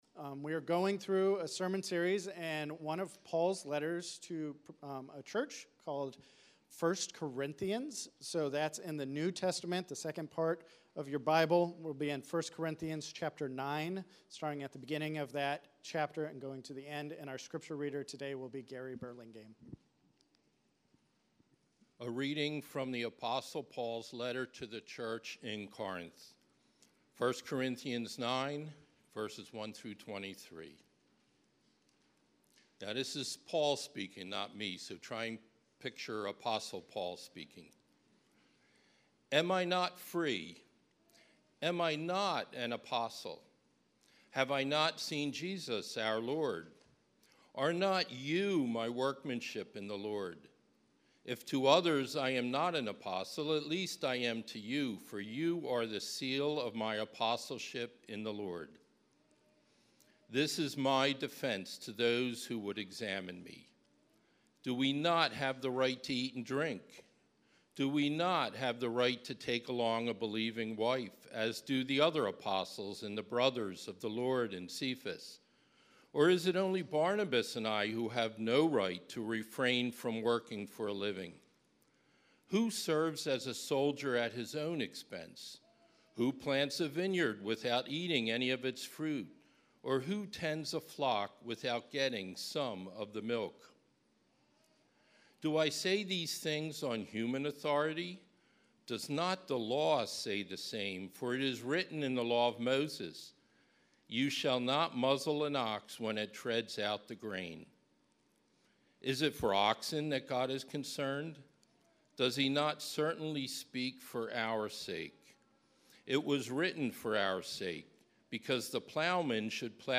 Sermons - Grace City Church of the Northeast